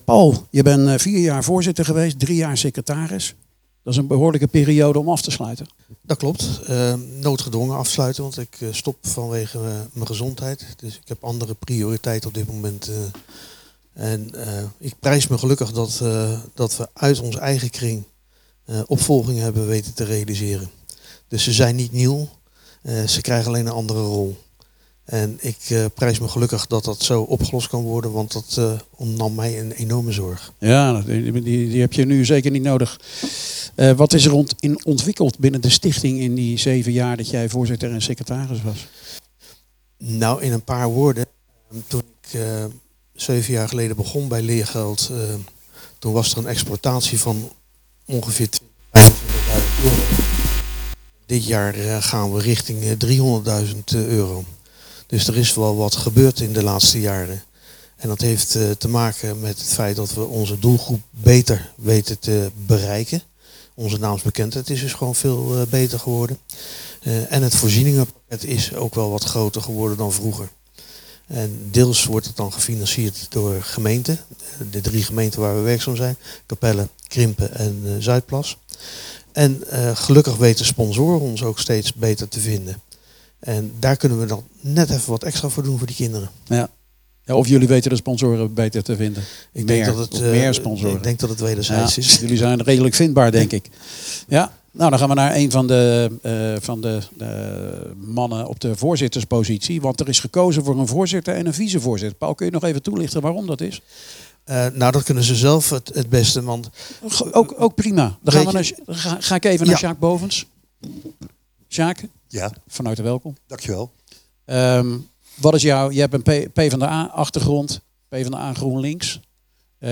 praat met de drie heren over behaalde resultaten en ambities voor de toekomst.